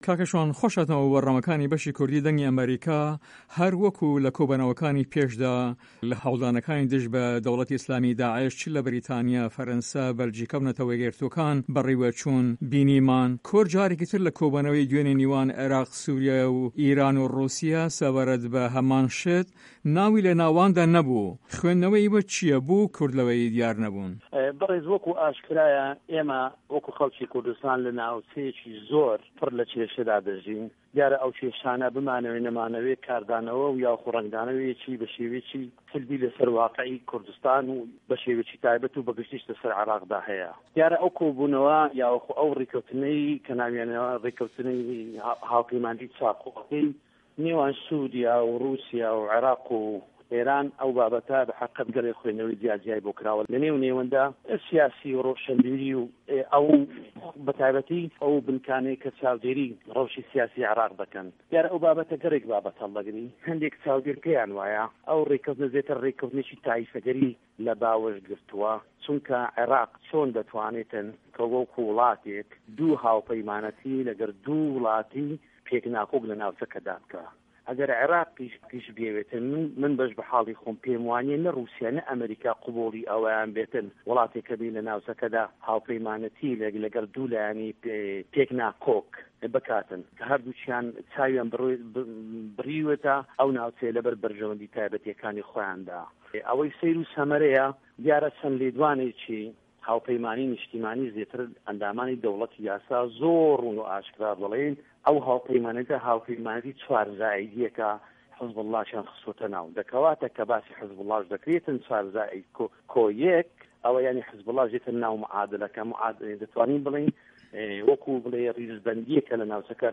شوان مەحەمەد ئەندام پەرلەمانی عێراق لەسەر لیستی پارتی دیمۆکراتی کوردستان لە هەڤپەیڤینێکدا لەگەڵ بەشی کوردی دەنگی ئەمەریکا دەڵێت" ئێمە وەکو خەڵکی کوردستان لە ناوچەیەکی زۆر پڕ لە کێشەدا دەژین، دیارە ئەو کێشانە بمانەوی و نەمانەوێ کاردانەوە و یاخود رەنگدانەوەی لەسەر هەرێمی کوردستان بە تاێبەتی و عێراقدا هەیە، من بەش بە حاڵی خۆم پێیم وا نییە نە روسیا و نە ئەمەریکا پەسەندی ئەوە بکەن وڵاتێک هەبێ لە ناوچەکەدا هاوپەیمانیەتی لەگەڵ دوو لایەنی پێک ناکۆک بکاتن کە هەردووکیان چاویان بریۆەتە ئەو ناوچە لەبەر بەرژەوەندیە تاێبەتیەکانی خۆیاندا.